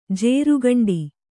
♪ jērugaṇḍi